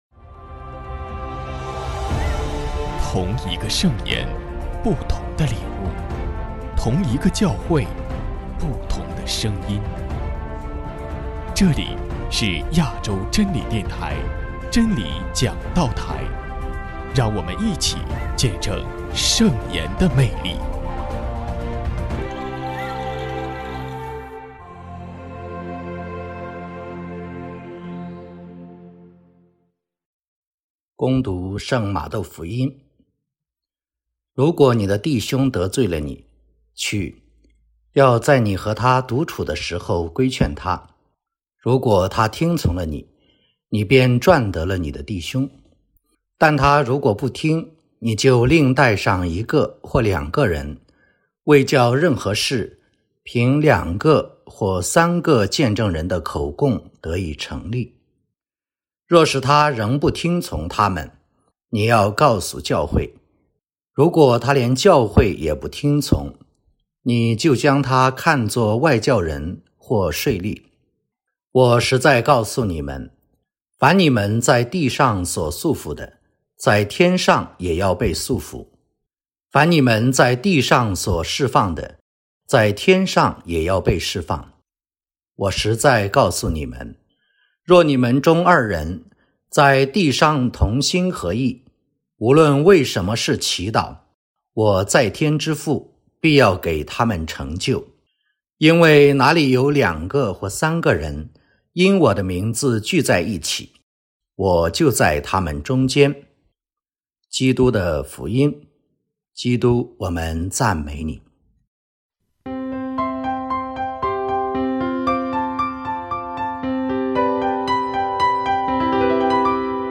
——基督的福音 证道主题：赚得弟兄之切 各位兄弟姐妹： 主内平安！